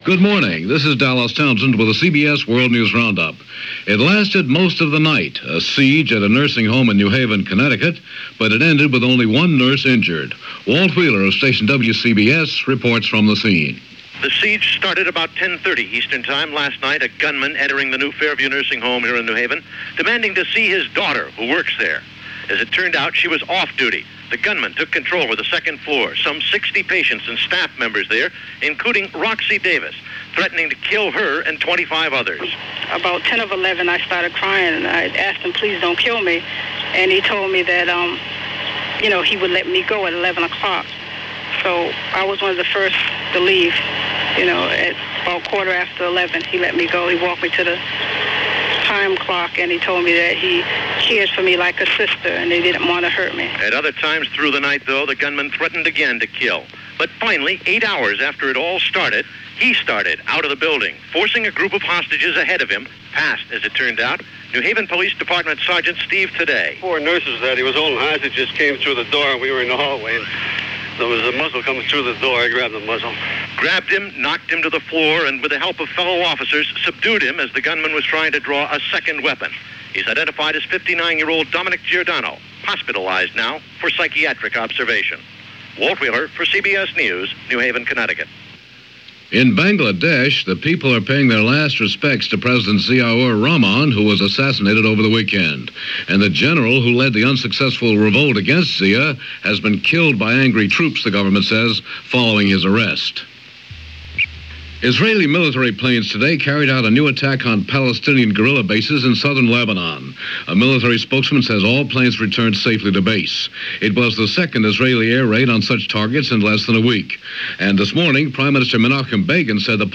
CBS World News Roundup + CBS Hourly News